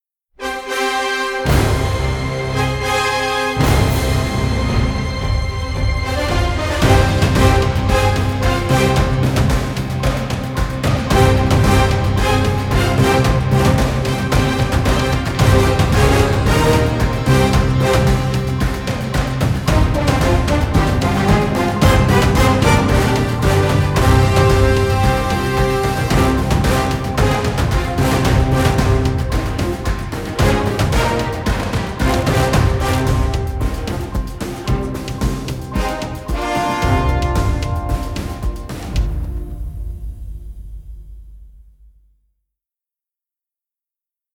orchestral score which offers extremely wide range of colors